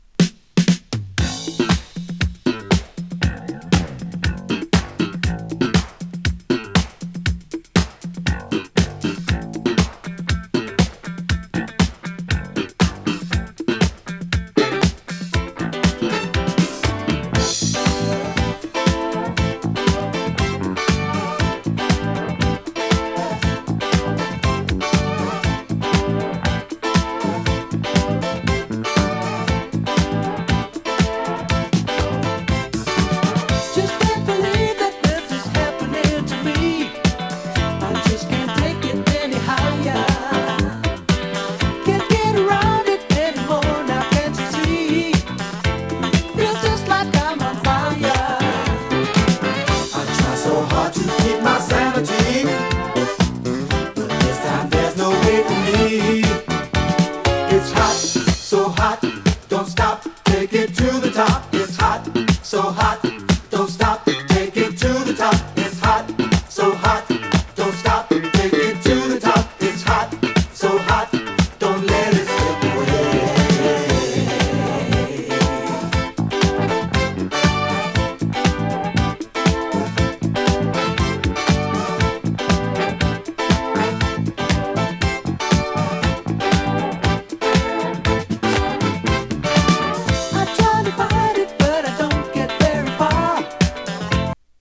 ユーロブギー
ユーロファンク